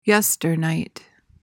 PRONUNCIATION:
(YES-tuhr-nyt)